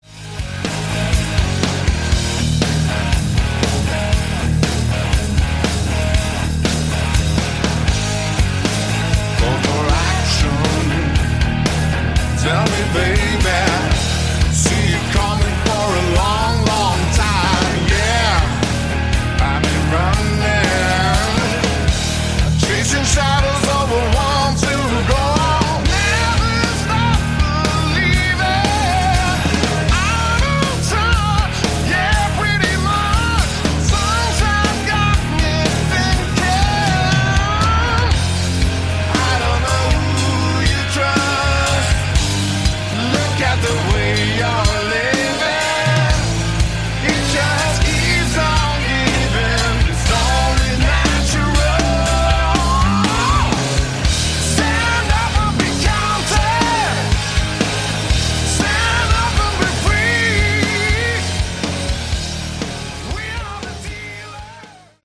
Category: Hard Rock
lead vocals
guitar, vocals
backing vocals